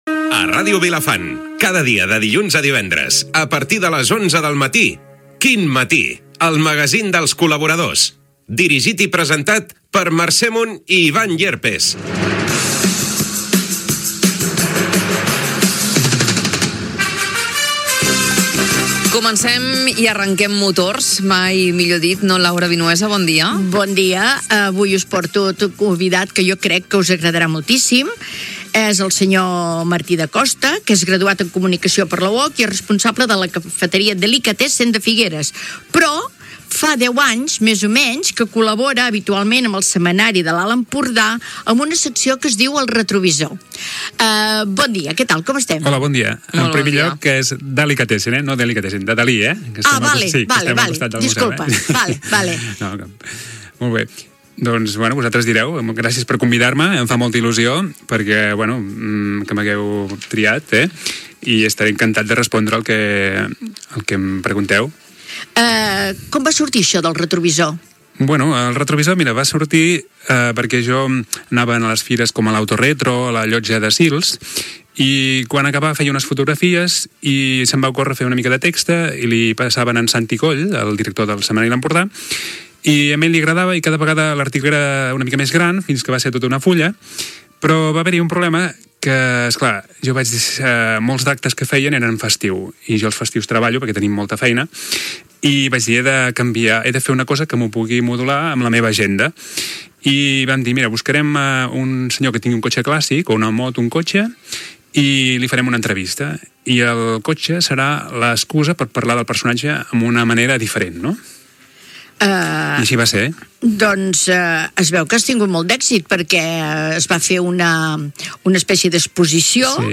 Pública municipal
Entreteniment
FM